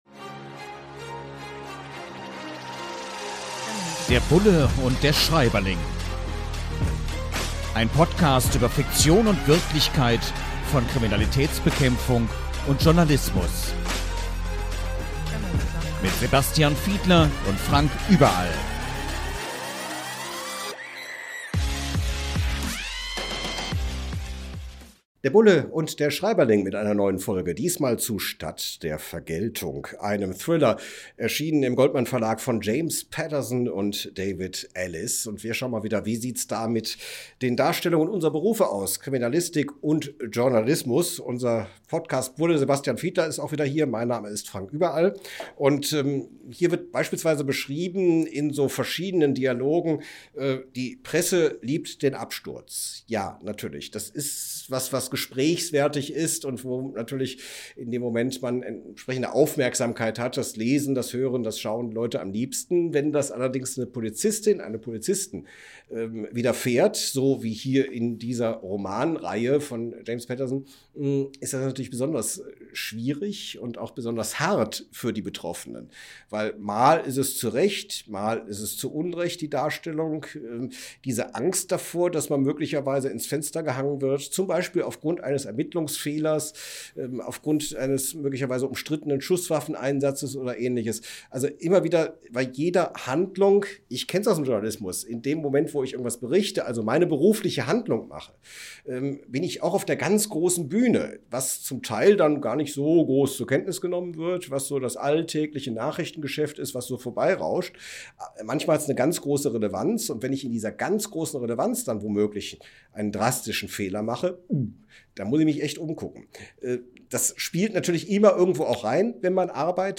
In launiger Atmosphäre tauschen sich die Beiden anhand von Aspekten aus, die in dem Buch beschrieben werden.